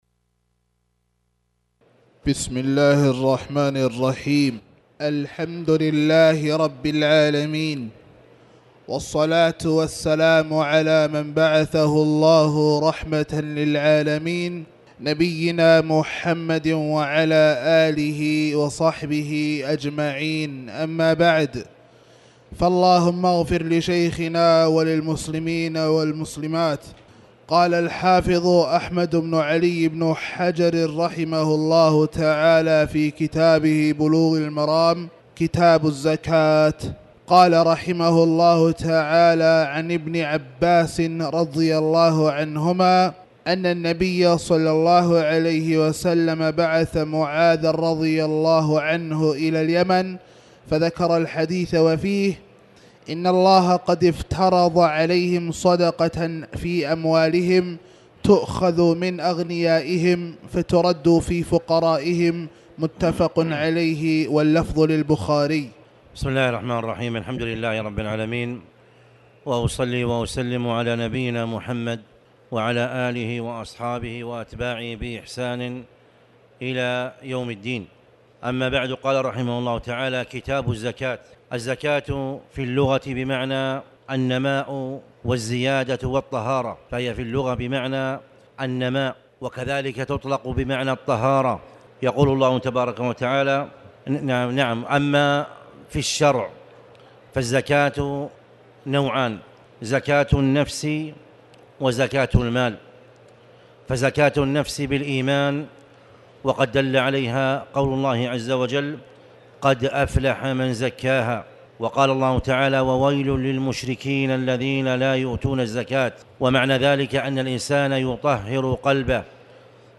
تاريخ النشر ٢٦ جمادى الآخرة ١٤٣٩ هـ المكان: المسجد الحرام الشيخ